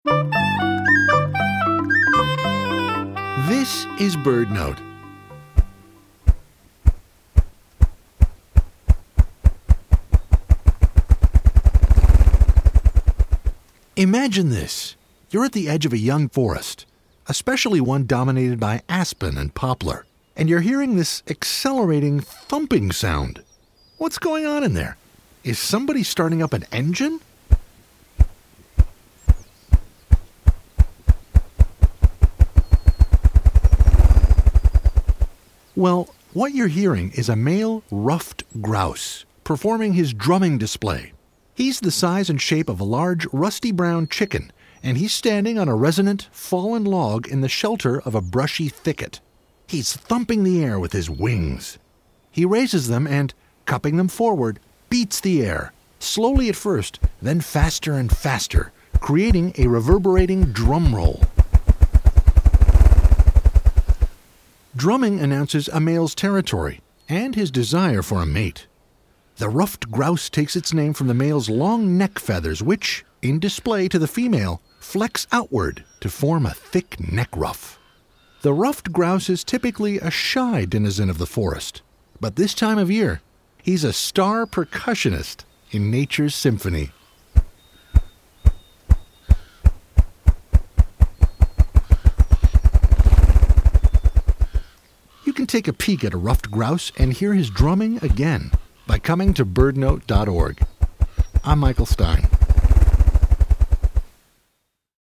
The male Ruffed Grouse stands on a resonant fallen log in the shelter of a brushy thicket, thumping the air with his wings. He raises them and – cupping them forward – beats the air, slowly at first, then faster, creating a reverberating drum roll. This announces his territory and his desire for a mate.